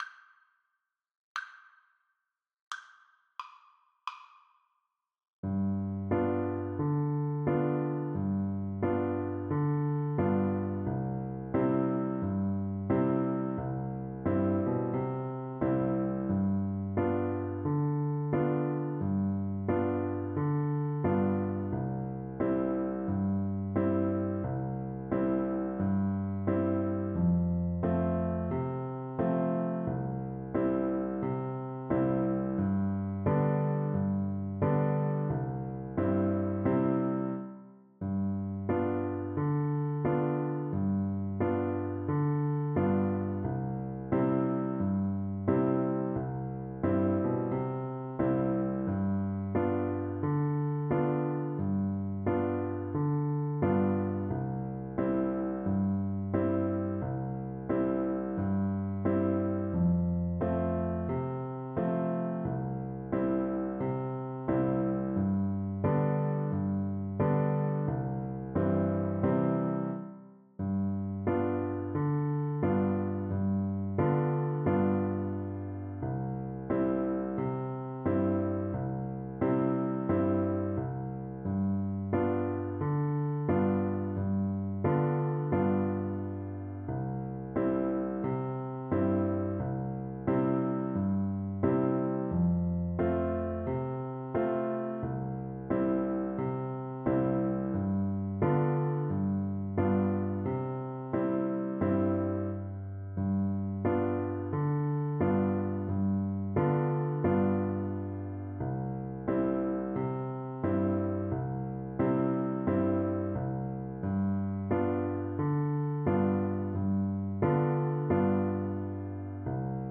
2/2 (View more 2/2 Music)
Moderate rag tempo = 136
Ragtime Music for French Horn